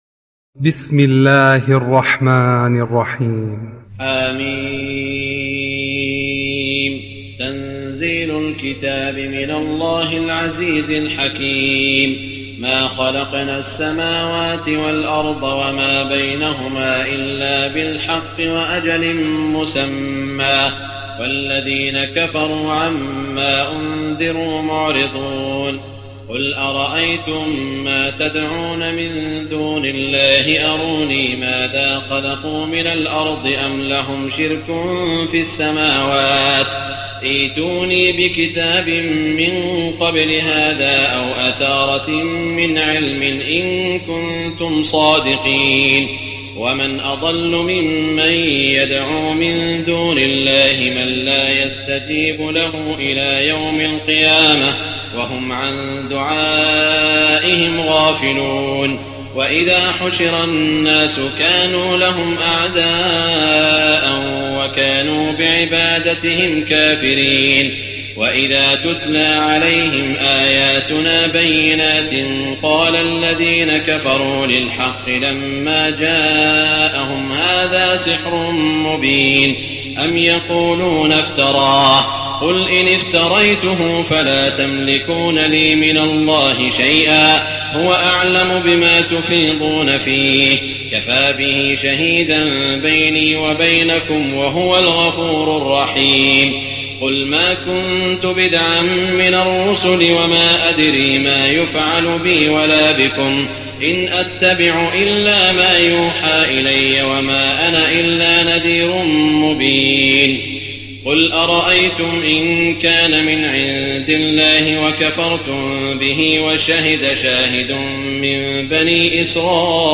تراويح ليلة 25 رمضان 1419هـ من سور الأحقاف و محمد و الفتح (1-17) Taraweeh 25 st night Ramadan 1419H from Surah Al-Ahqaf and Muhammad and Al-Fath > تراويح الحرم المكي عام 1419 🕋 > التراويح - تلاوات الحرمين